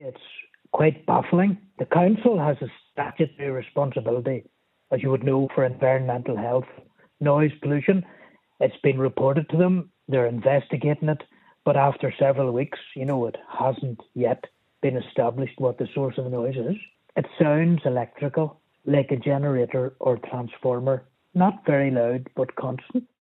Local Councillor Barry McElduff says they’ve been trying to figure it out, but have so far been unsuccessful…………